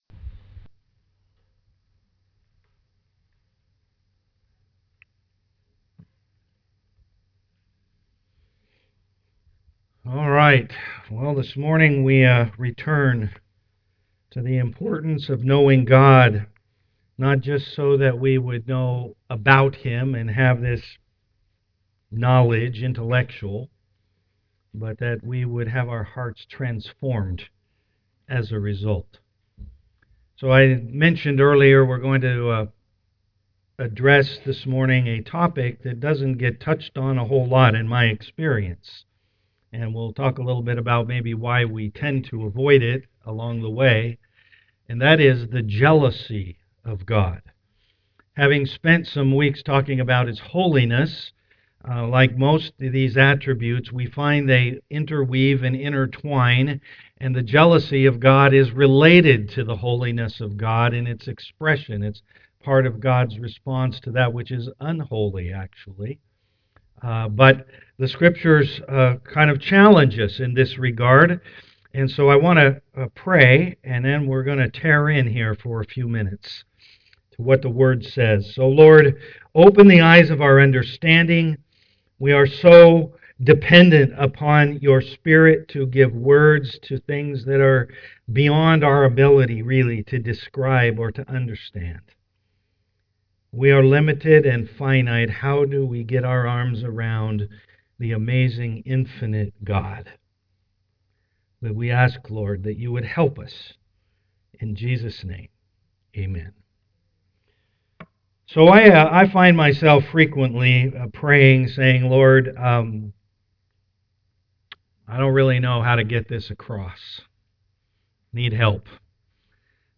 Know God Service Type: am worship Download Files Notes Bulletin Topics: Jealousy , love , worship « Know God